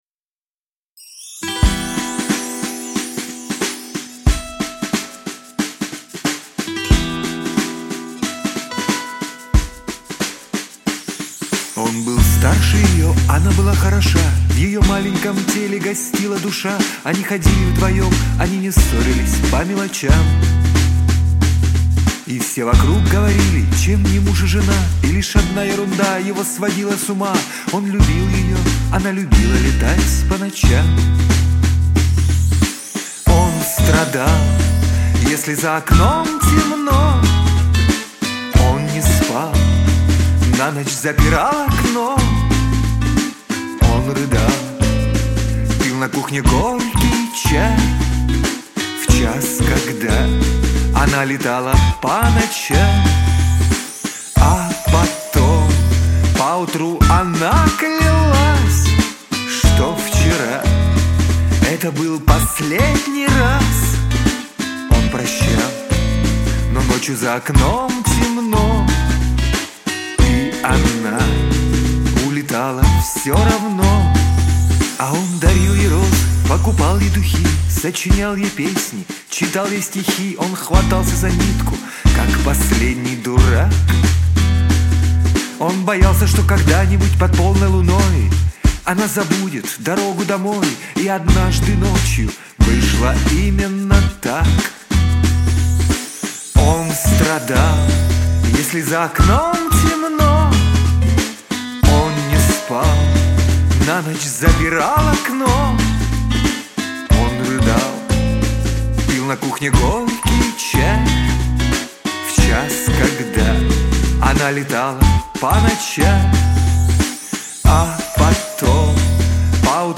Хороший вокал, интонирование и эмоции.